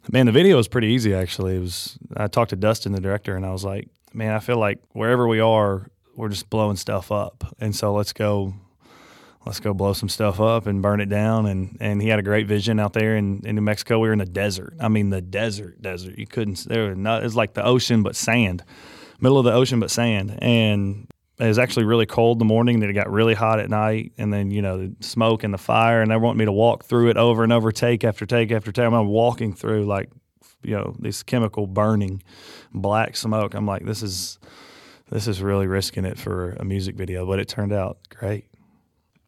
PARKER MCCOLLUM TALKS ABOUT SHOOTING THE VIDEO FOR "BURN IT DOWN."